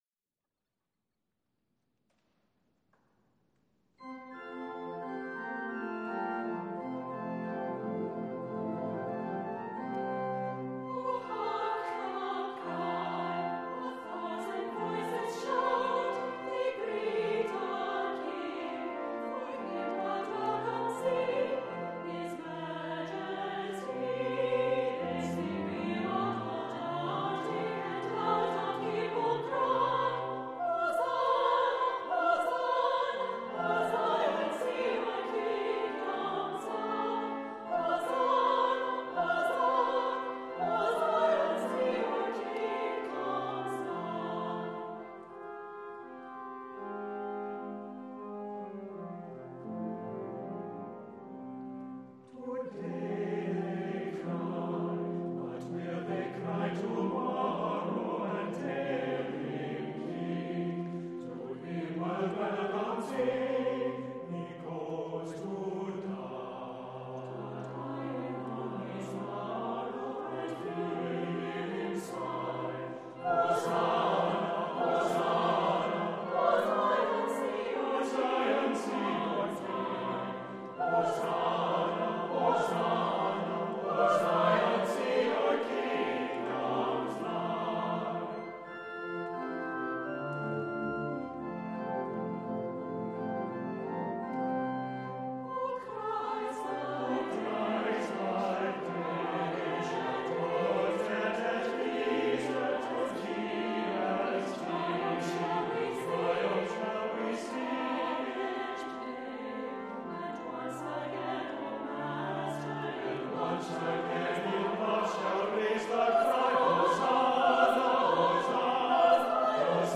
• Music Type: Choral
• Voicing: SATB
• Accompaniment: Organ
*Music is from a famous French carol